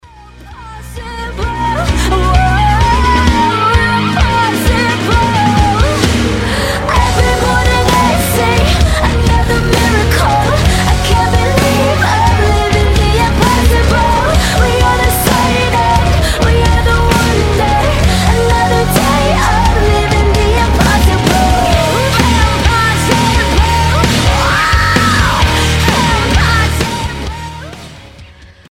Рок рингтоны